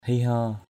/hi-hɔ:/ 1.